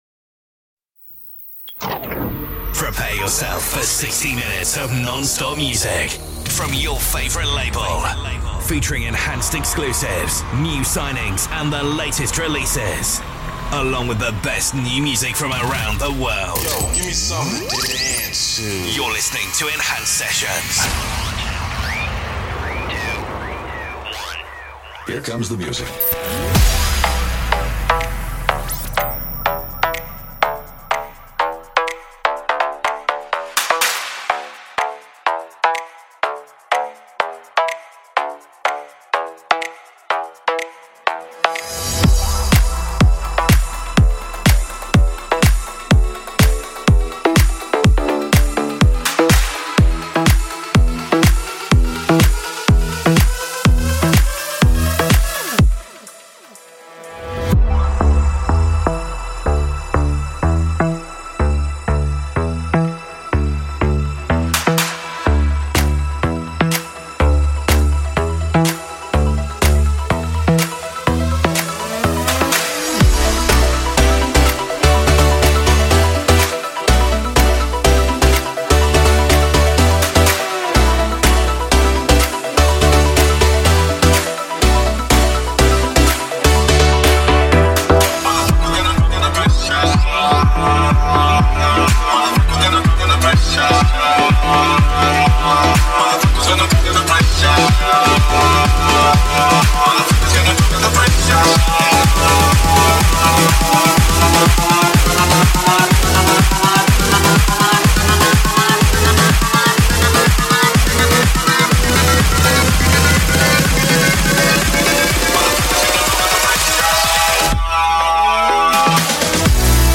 Liveset/DJ mix